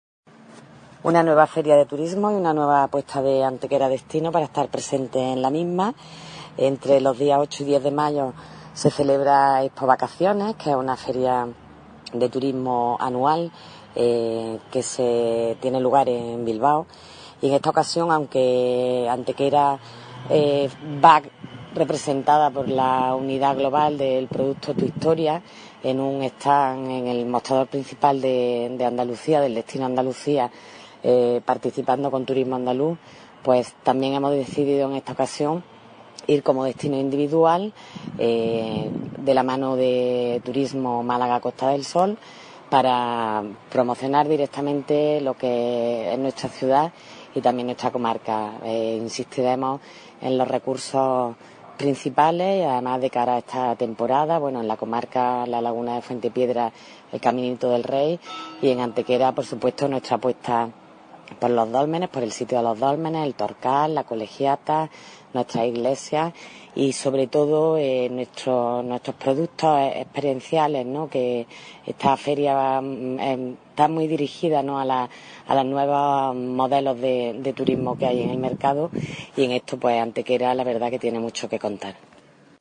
Cortes de voz B. Jiménez 970.44 kb Formato: mp3